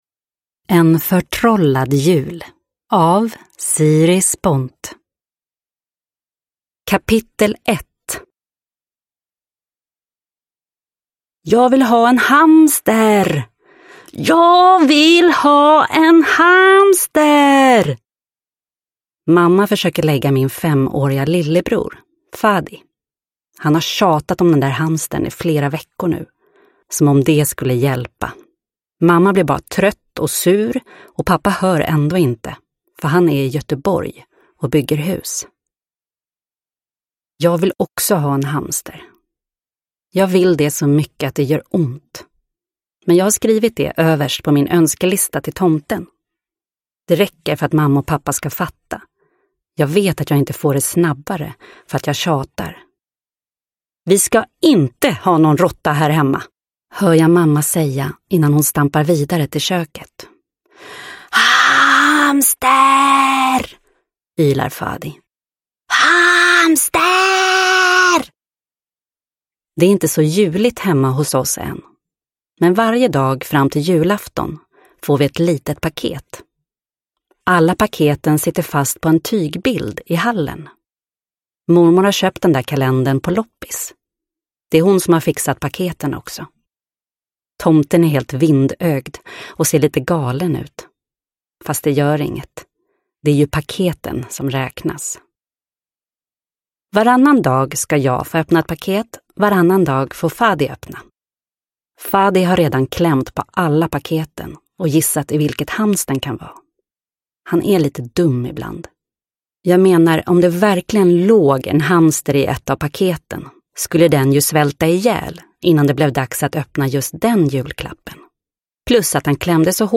En förtrollad jul – Ljudbok – Laddas ner
För femte året i rad kommer adventsboken alla längtar efter - en högläsningsbok i 24 kapitel som räknar ner till julafton.